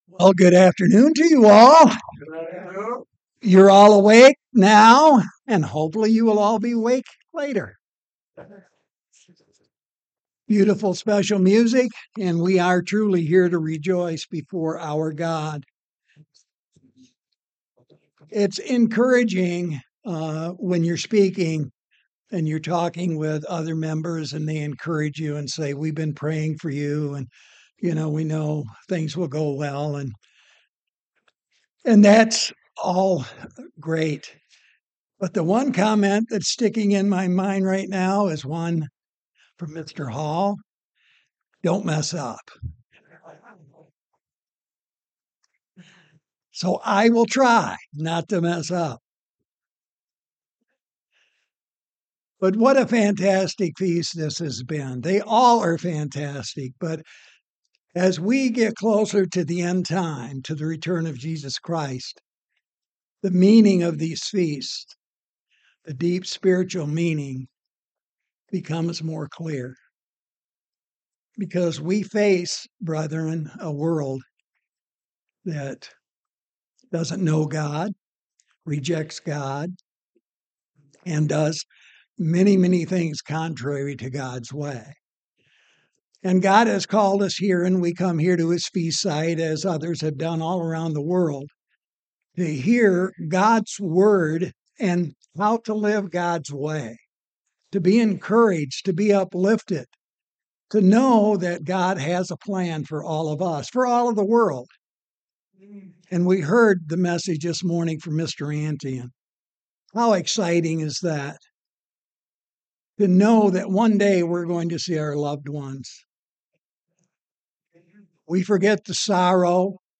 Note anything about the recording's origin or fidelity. This sermon was given at the Cincinnati, Ohio 2024 Feast site.